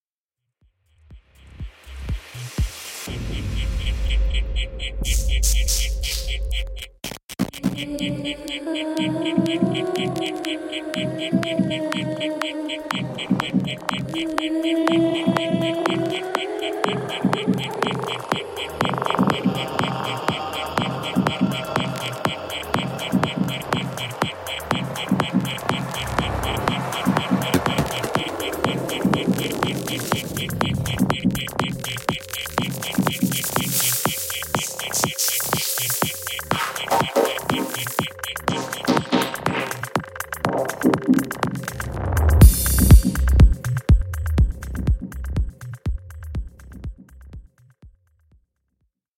Techno Sound FX
Всем привет! Помогите пожалуйста накрутить звук, он начинается открытым в начале ямы потом закрывается и в конце опять катоф открывается и перед дропом закрывается снова